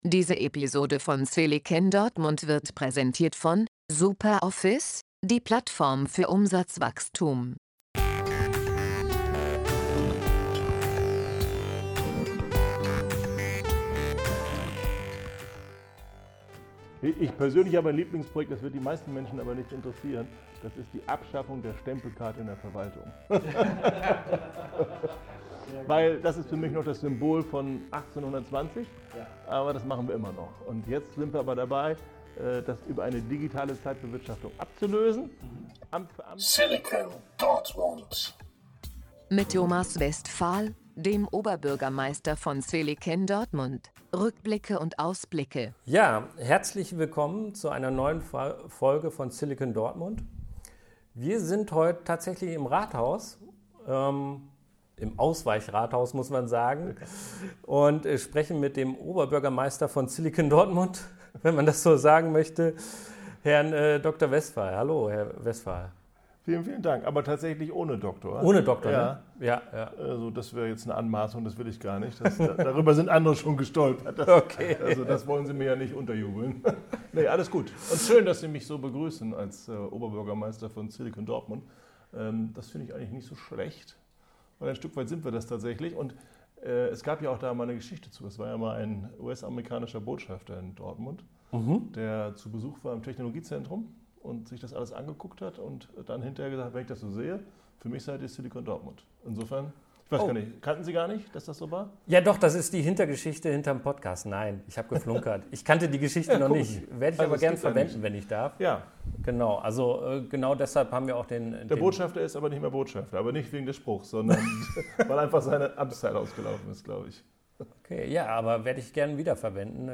Wir trafen das Stadtoberhaupt von (Silicon-)Dortmund - Oberbürgermeister Thomas Westphal - zum Interview.